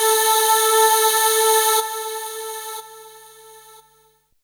voiTTE64006voicesyn-A.wav